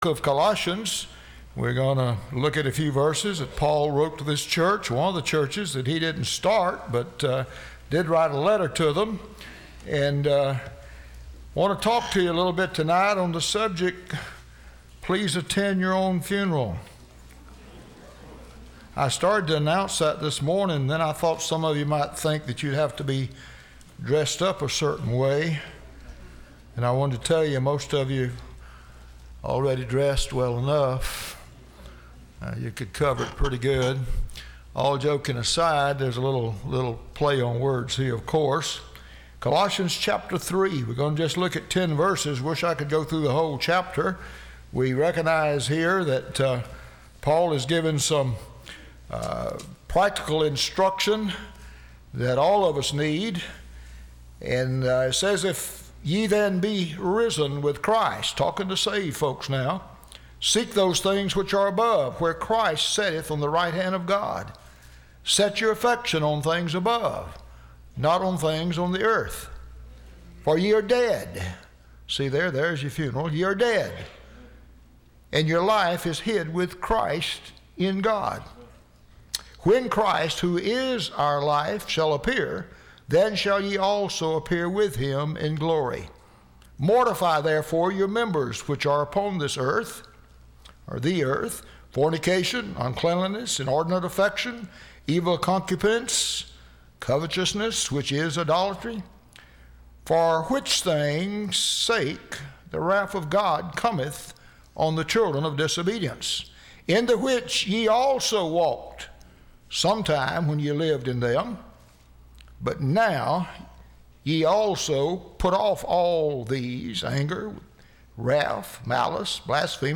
Listen to Message
Service Type: Sunday Evening